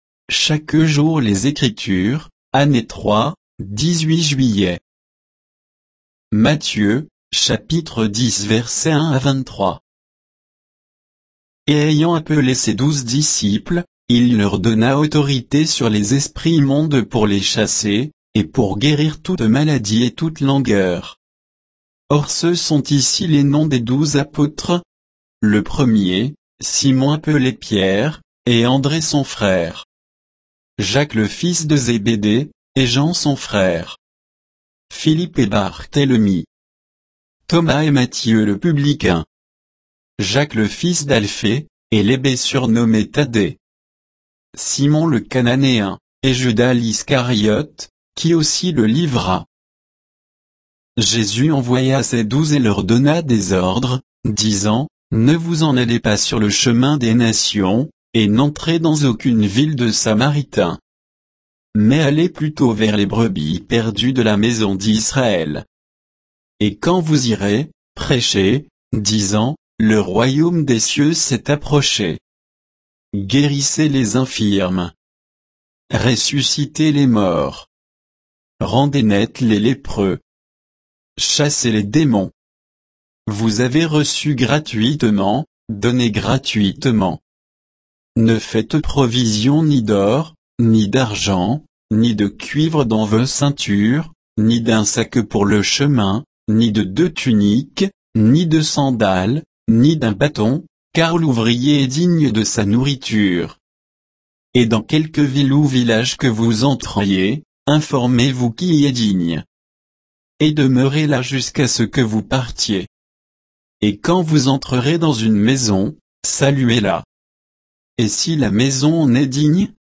Méditation quoditienne de Chaque jour les Écritures sur Matthieu 10